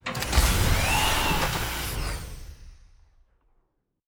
Door 1 Open 2.wav